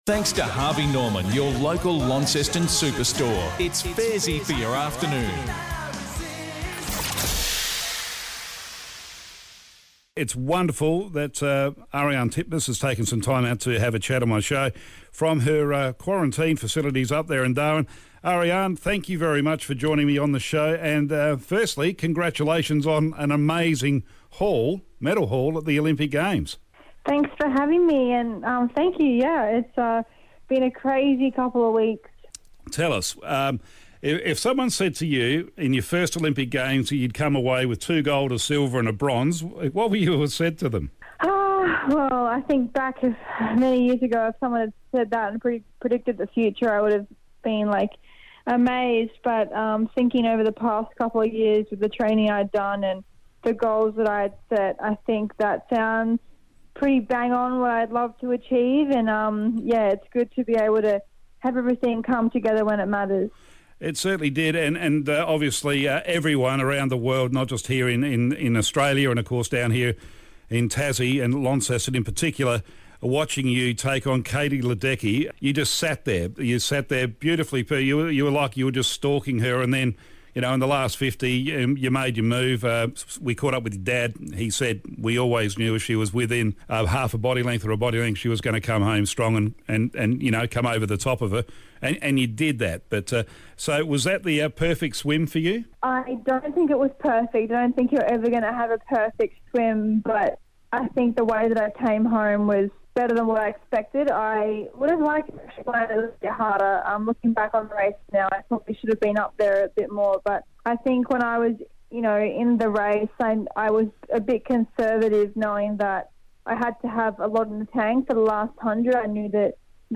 live from quarantine.